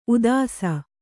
♪ udāsa